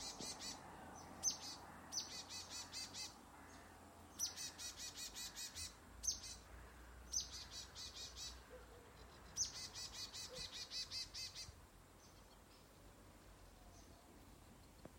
Birds -> Tits ->
Marsh Tit, Poecile palustris